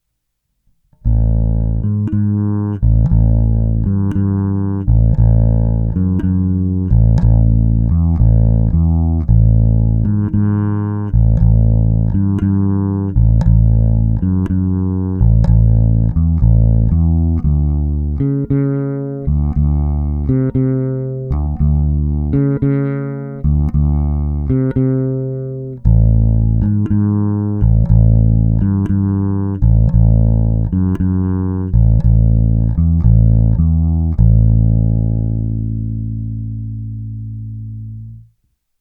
Následující nahrávky jsou vyvedeny rovnou do zvukovky a kromě normalizace ponechány bez úprav.
Hráno všechno s polohou pravé ruky u krku.
Oba snímače